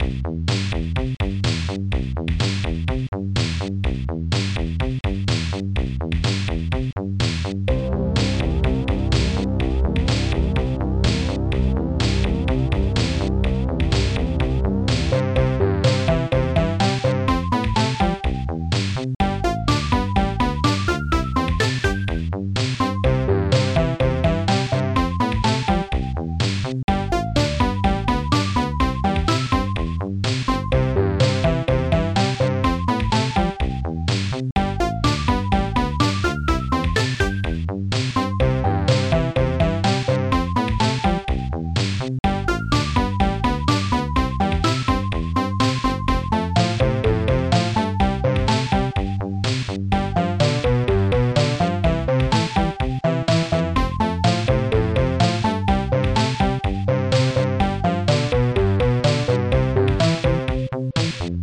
Protracker Module
2 channels
Instruments digdug dreambells analogstring leader popsnare2 bassdrum2